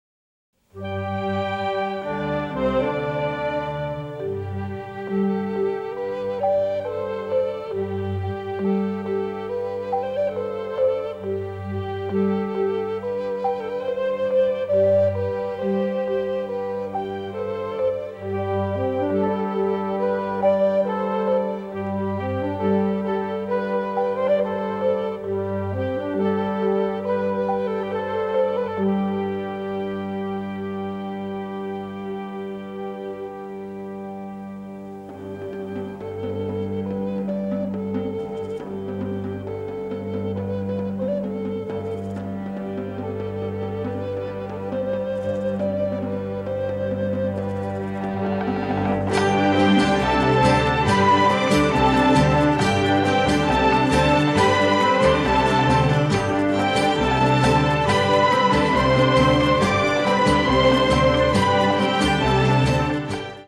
lush orchestral score